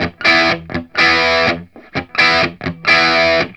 RIFF1-125E+.wav